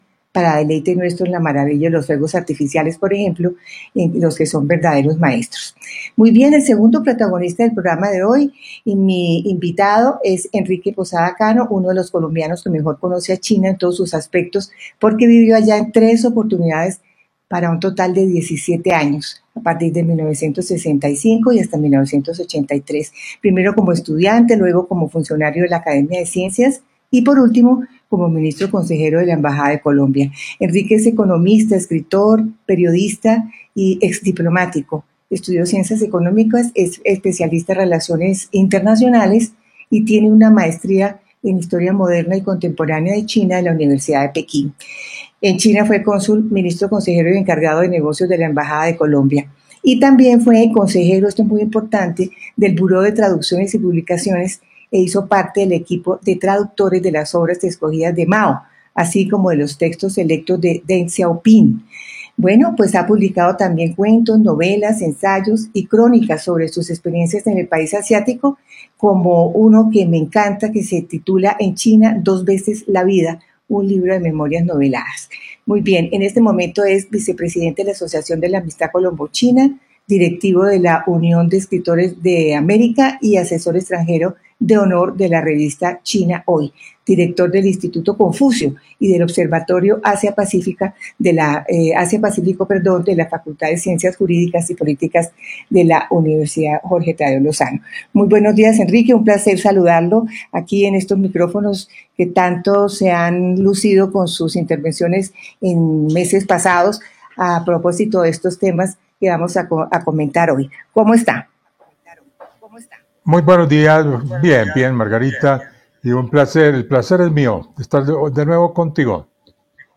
Escuche aquí la entrevista en Radio Nacional de Colombia.